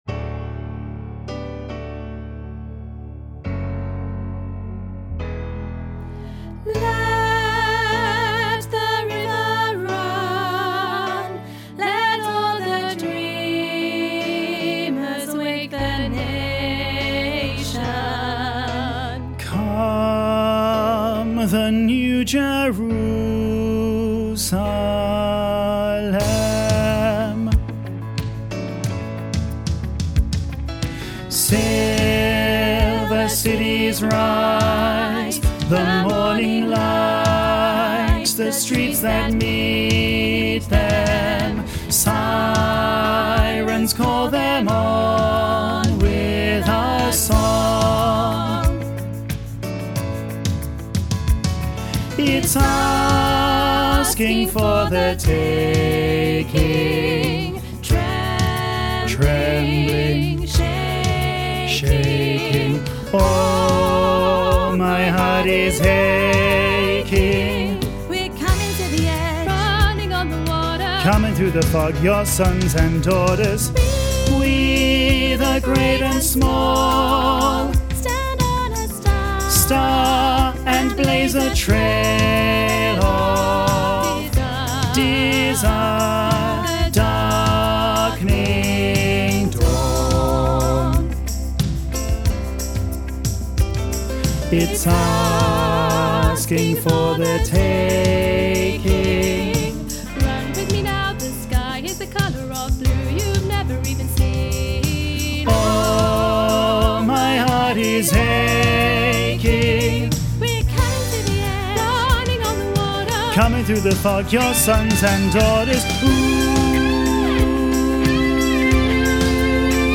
Listen to bass track with soprano and alto accompaniment